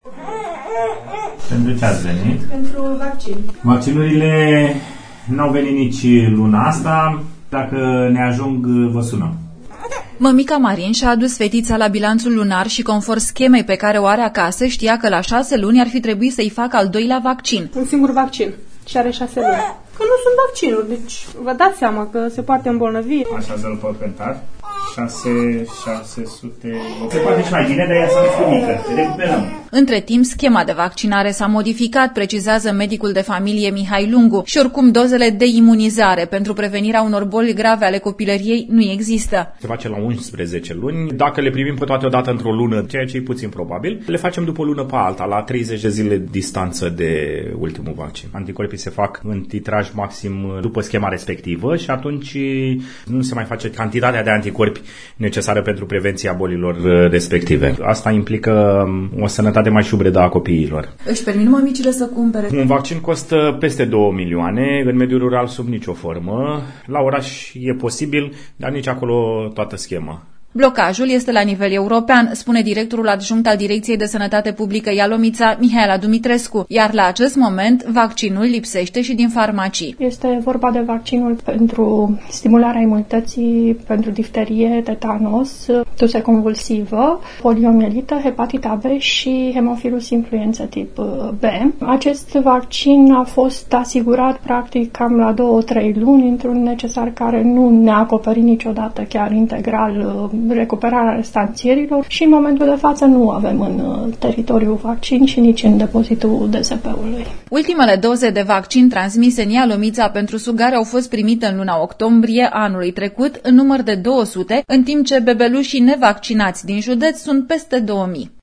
reportaj-bebelusi-nevaccinati.mp3